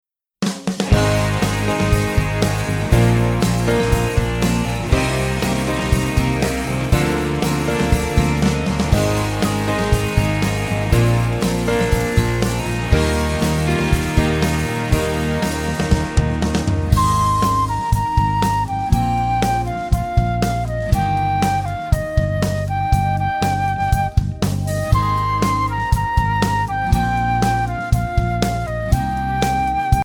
Voicing: Flute w/ Audio